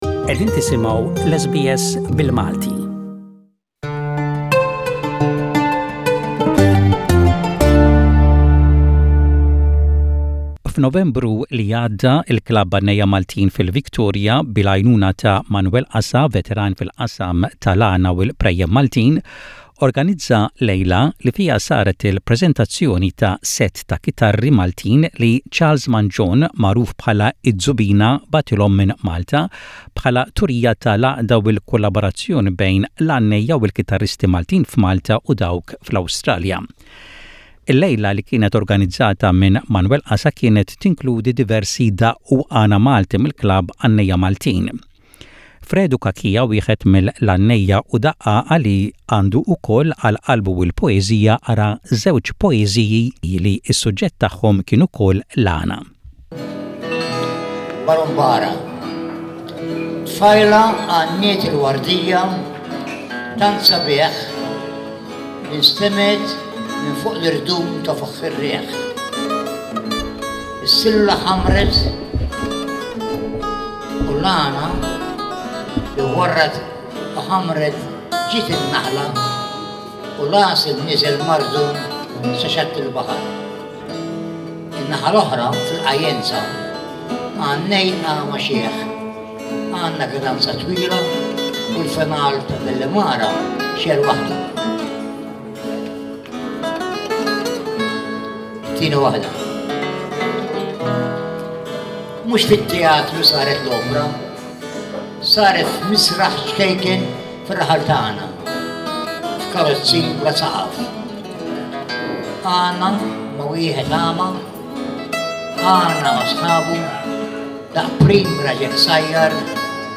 poetry presentation
reads two of his poems during a folk night presentation when a set of guitars were officially presented to the Klabb Għannejja Maltin in Victoria